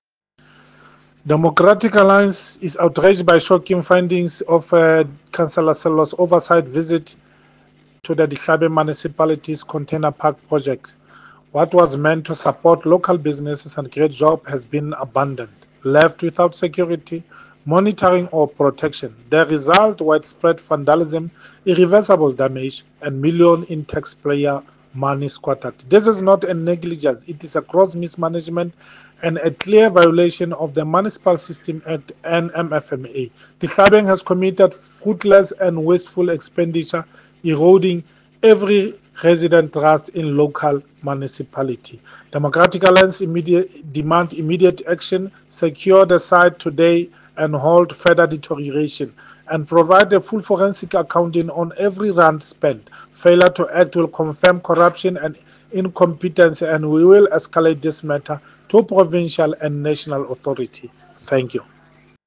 Sesotho soundbites by Cllr Sello Makoena